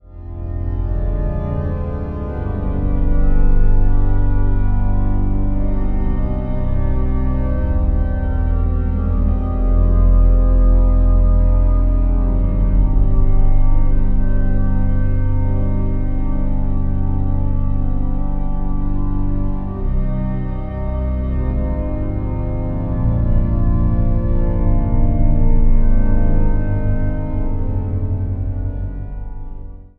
Improvisaties op zendingspsalmen